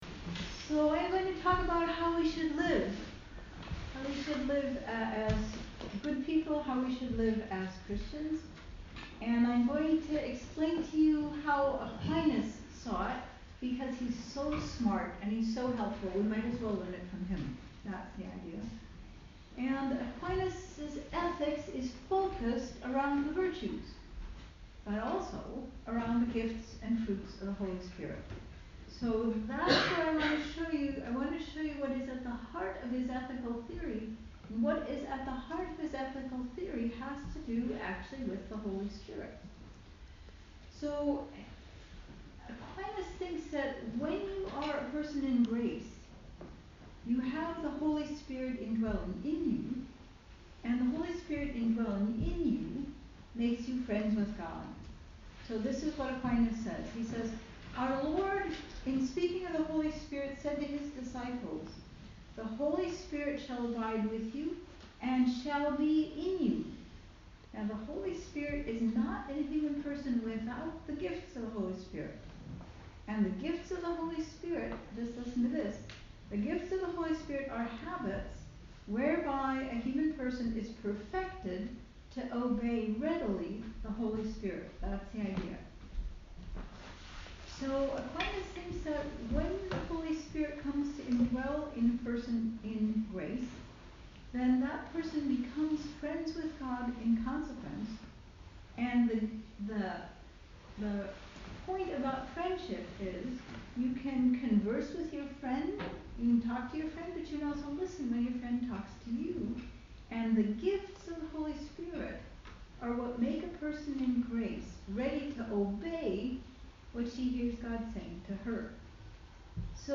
This lecture was given at St. Savior's Priory (Dublin) on 9 October 2019.